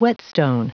Prononciation du mot whetstone en anglais (fichier audio)
whetstone.wav